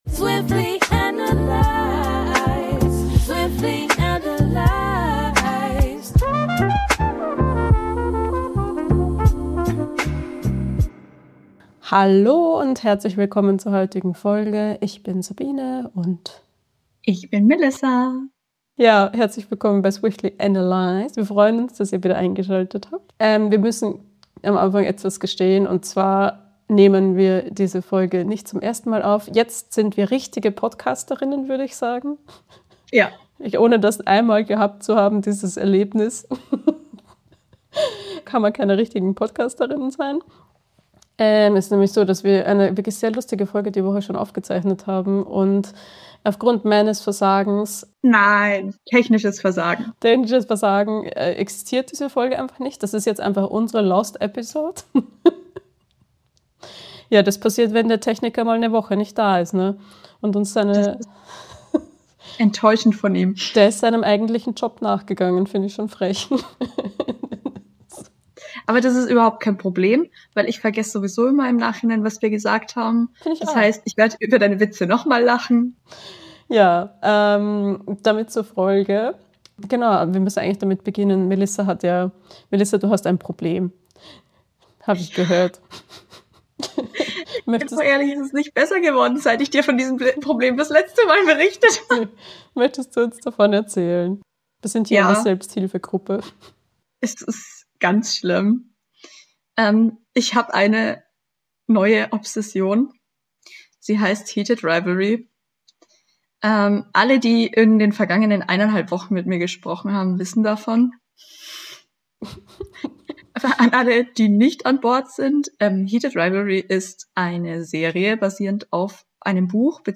Folge 8: Who's your Daddy? Aufnahme aus dem Cottage (Versuch 2) ~ Swiftly Analyzed Podcast